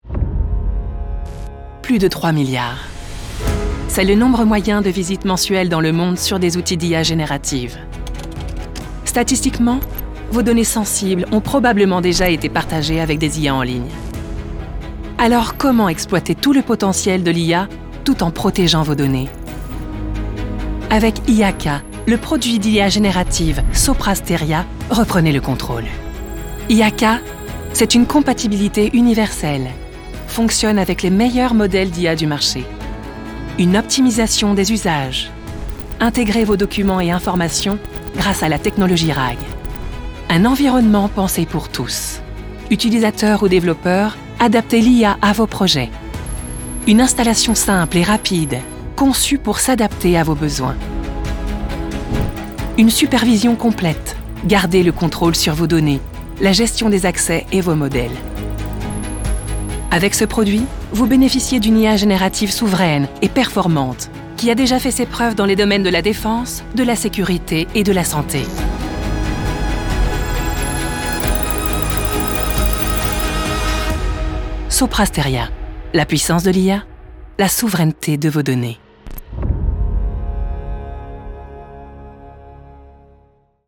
Casting narration
30 - 50 ans - Mezzo-soprano